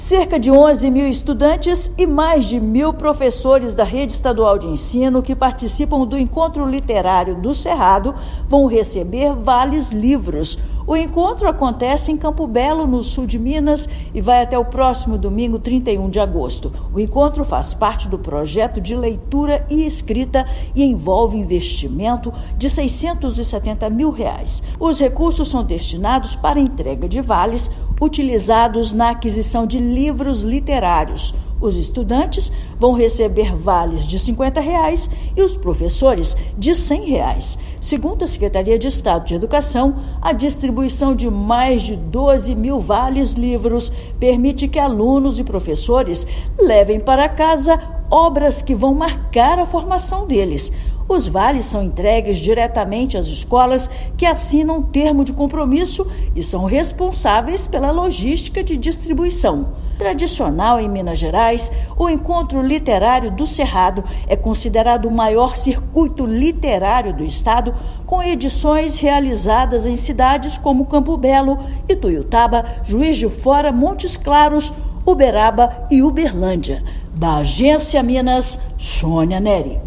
Ação incentiva a leitura entre estudantes e professores da rede estadual durante o Encontro Literário do Cerrado. Ouça matéria de rádio.